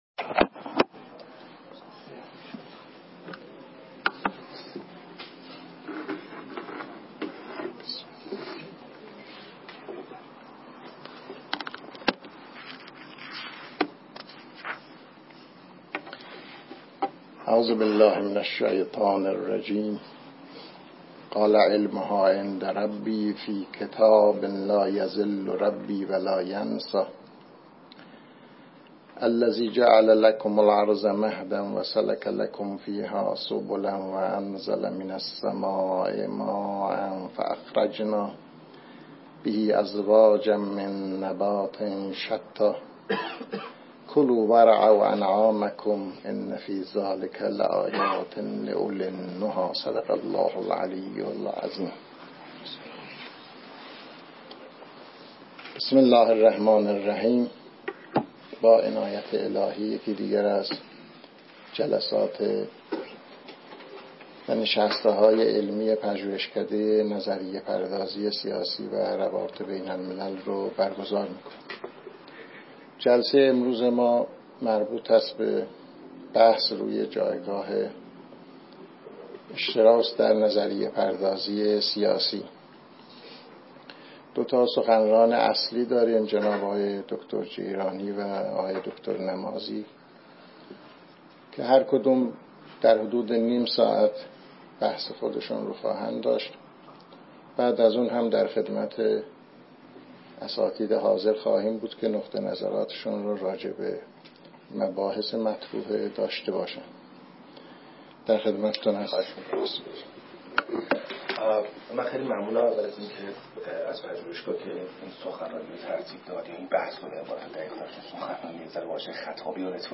هشتادو سوّمین نشست پژوهشکده نظریه پردازی سیاسی و روابط بین الملل